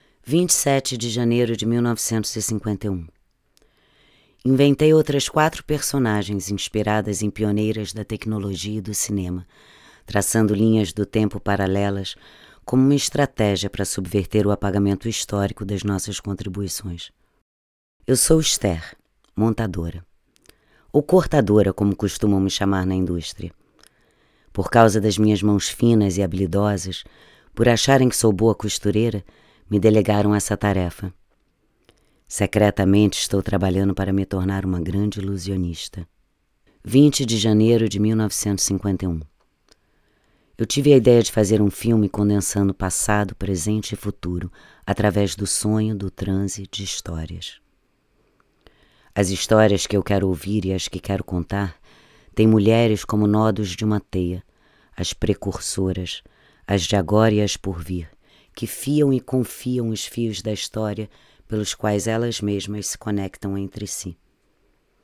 Locutor
Hablante nativo